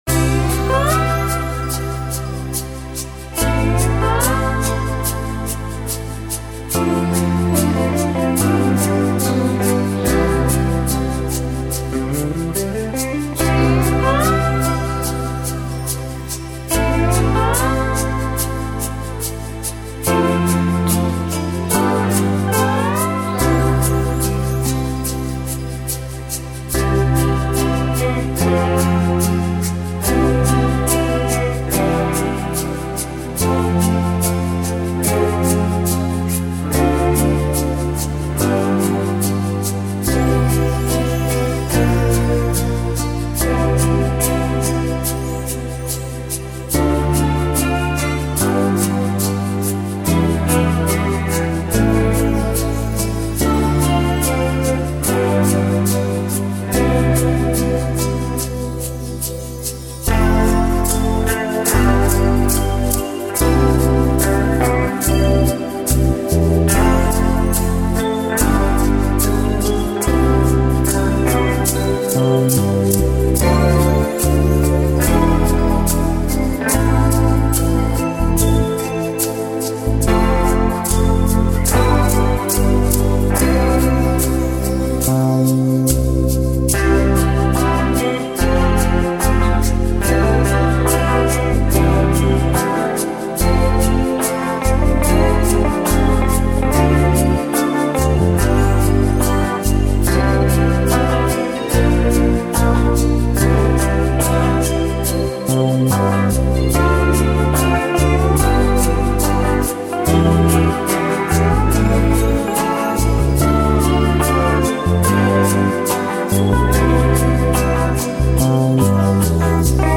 Главная / Песни для детей / Песни про маму